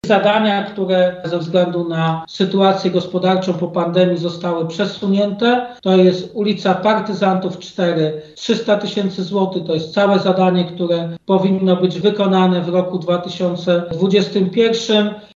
Mówił o tym prezydent Stalowej Woli Lucjusz Nadbereżny: